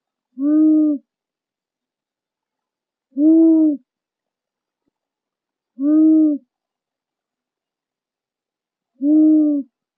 トラフズク｜日本の鳥百科｜サントリーの愛鳥活動
「日本の鳥百科」トラフズクの紹介です（鳴き声あり）。平たい顔をしているフクロウの仲間。